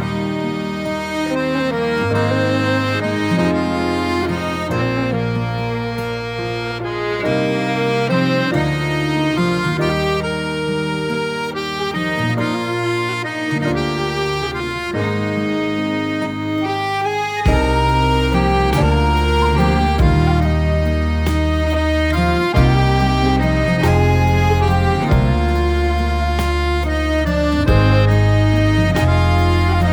• Traditional Folk